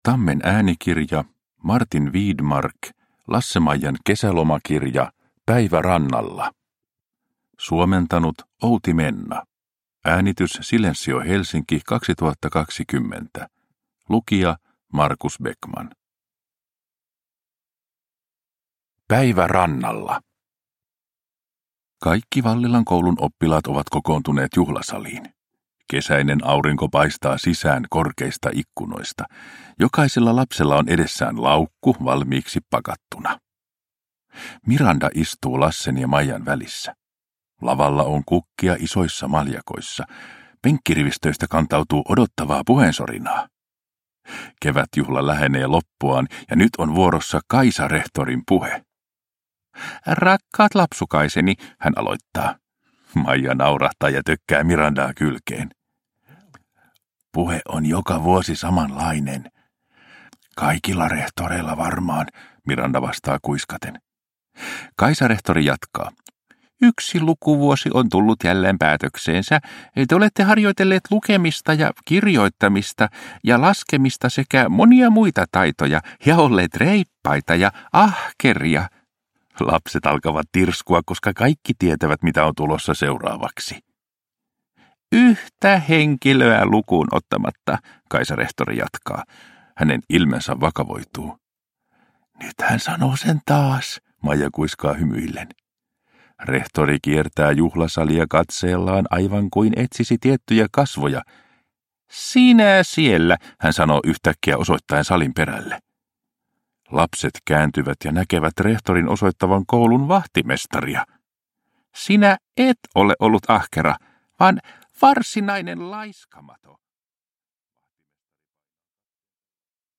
Lasse-Maijan kirjakesäloma 3 – Ljudbok